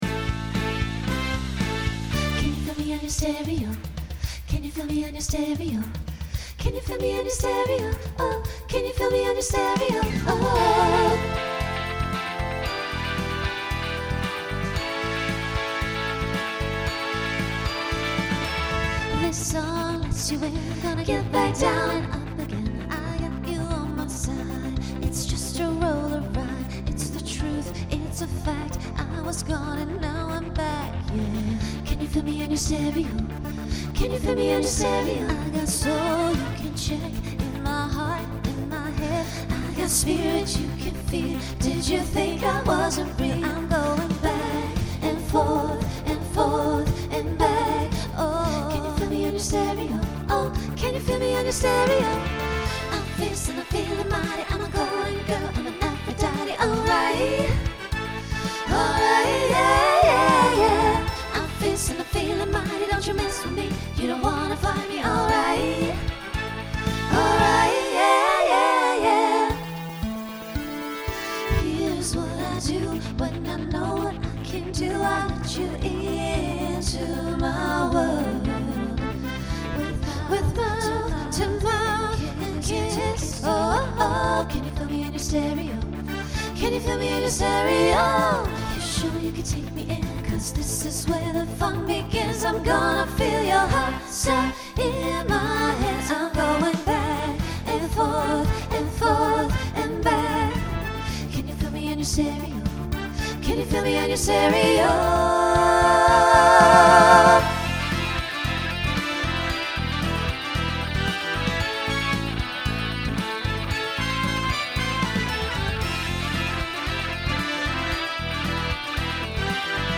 Genre Pop/Dance
Opener Voicing SSA